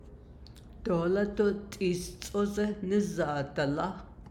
Dialect: Crownpoint